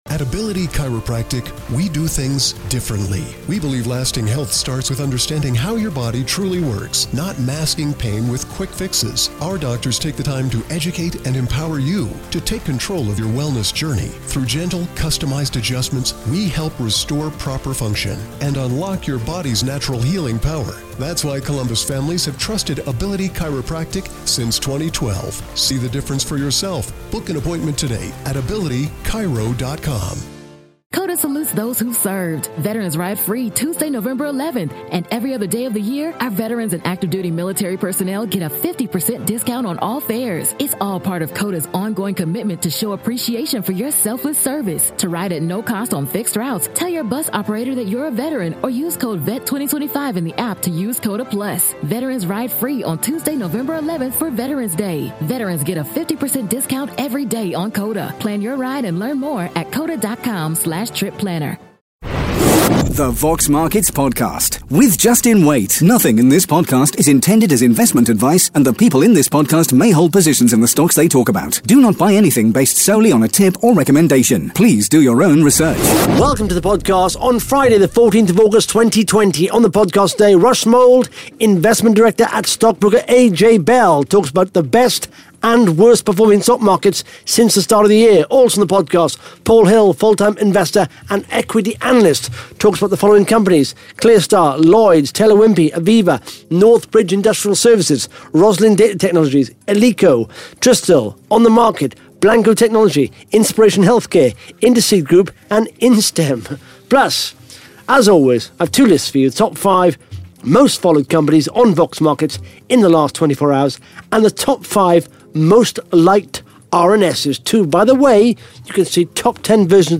(Interview starts at 17 minutes 36 seconds) Plus the Top 5 Most Followed Companies & the Top 5 Most liked RNS’s on Vox Markets in the last 24 hours.